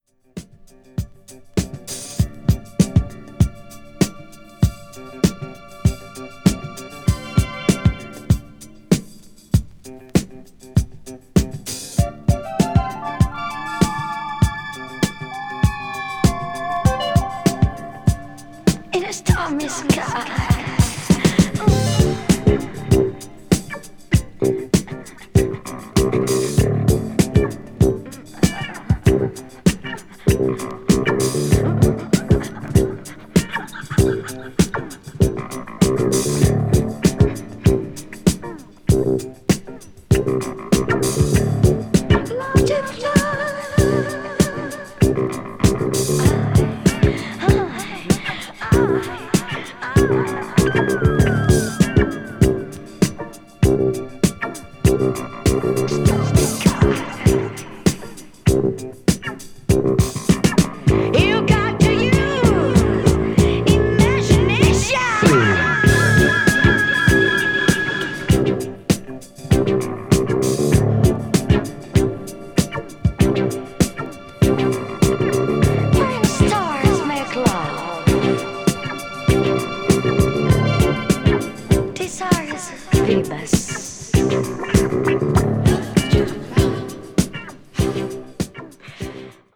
cosmic   disco related   funk   obscure dance   synth disco